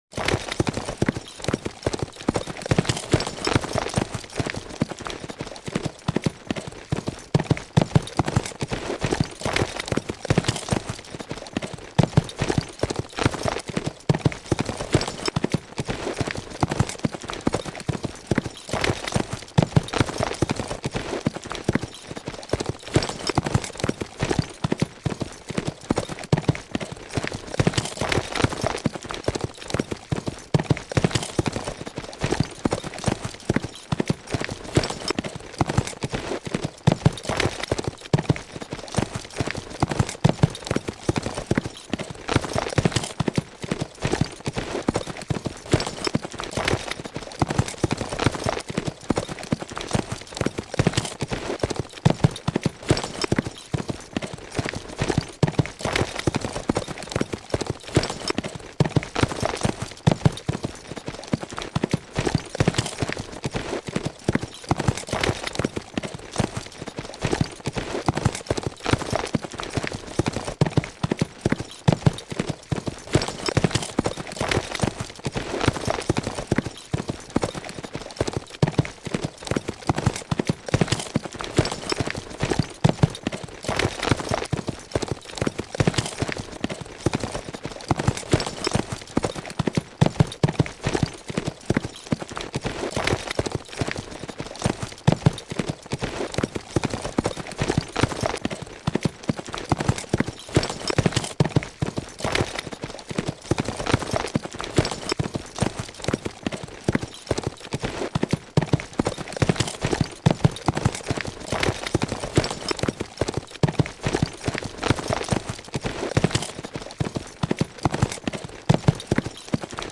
دانلود آهنگ اسب 67 از افکت صوتی انسان و موجودات زنده
جلوه های صوتی
برچسب: دانلود آهنگ های افکت صوتی انسان و موجودات زنده دانلود آلبوم انواع صدای شیهه اسب از افکت صوتی انسان و موجودات زنده